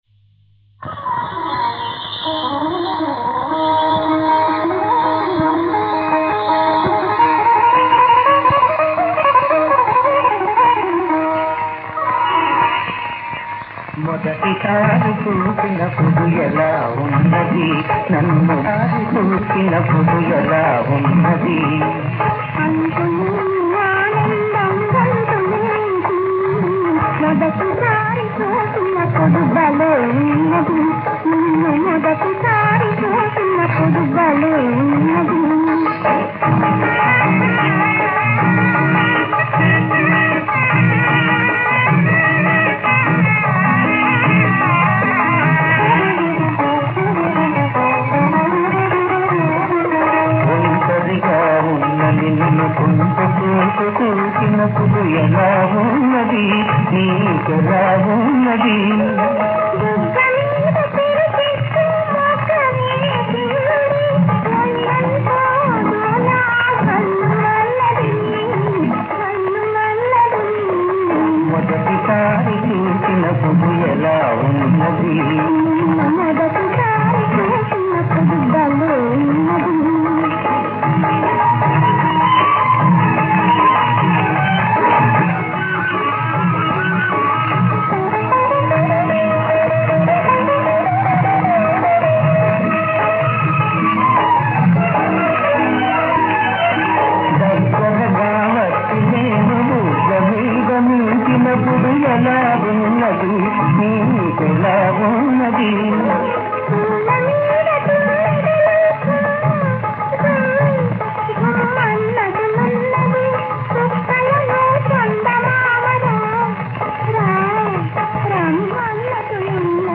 పాట క్వాలిటీ అంతంతగా ఉంది.